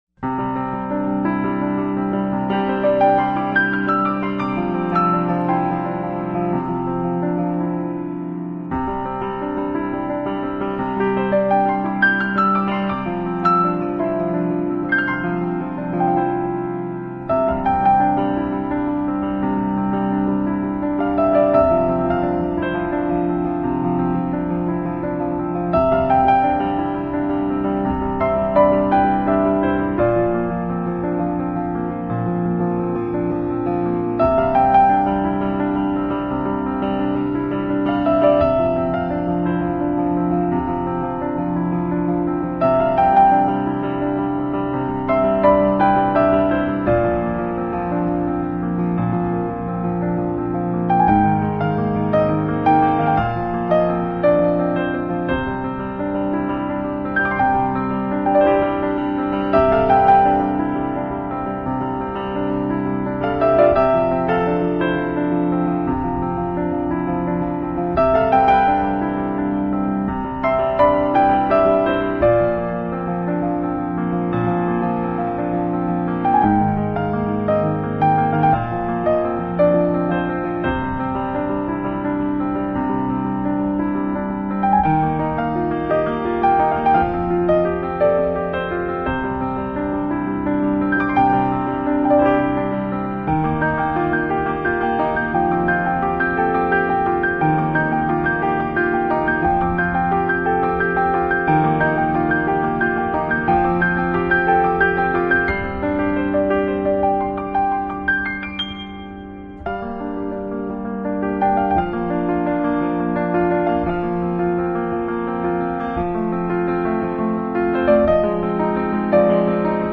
抒情钢琴